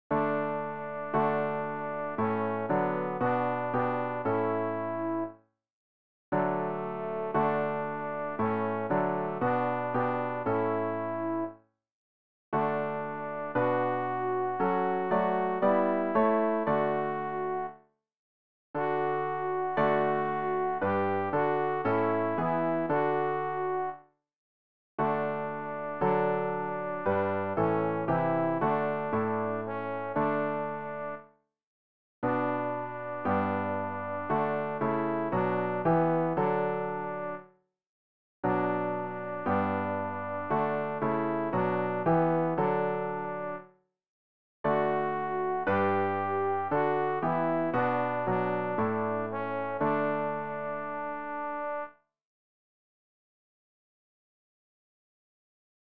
Übehilfen für das Erlernen von Liedern
alt-rg-102-halleluja-singt-dem-herren.mp3